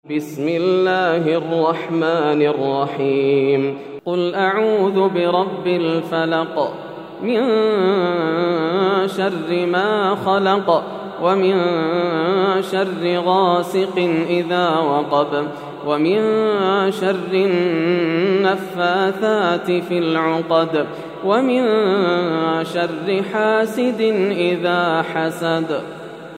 سورة الفلق > السور المكتملة > رمضان 1431هـ > التراويح - تلاوات ياسر الدوسري